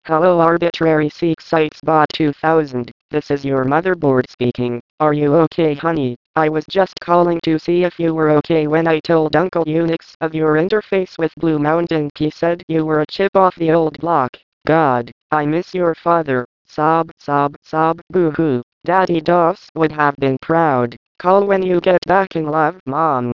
call from Bot 2000's Motherboard on answering machine.
using Bell labs voice synthesis